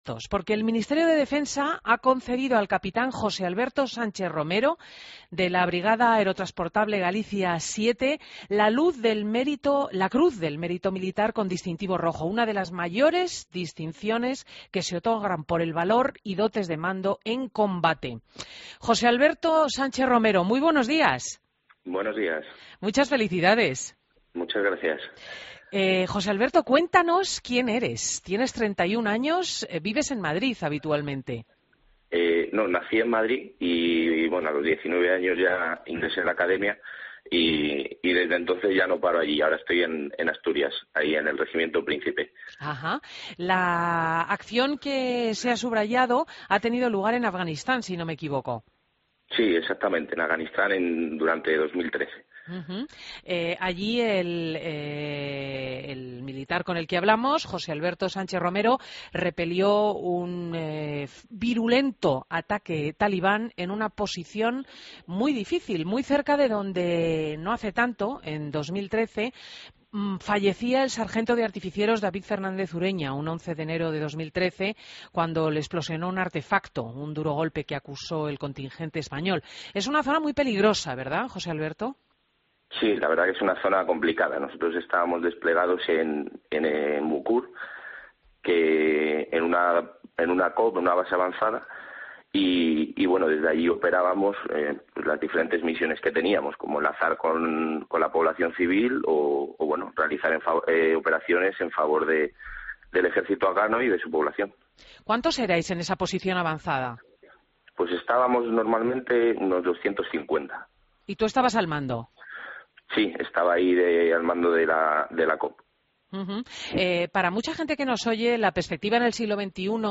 Entrevistas en Fin de Semana Entrevista